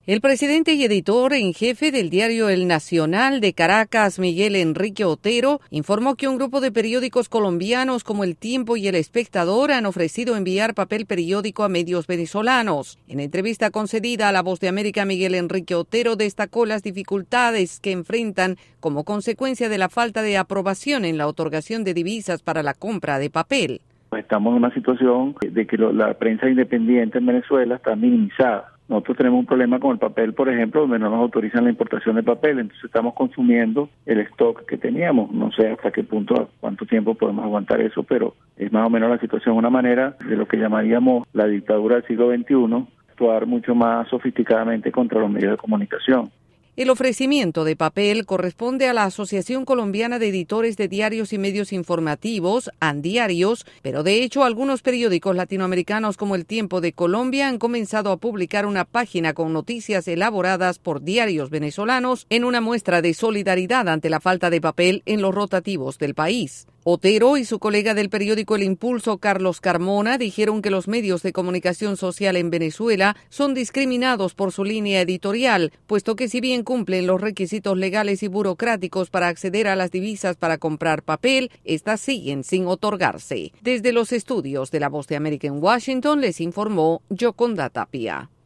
Los diarios en Venezuela enfrentan una crisis sin precedentes debido a la falta de otorgación de divisas para la compra de papel. Desde la Voz de América en Washington DC informa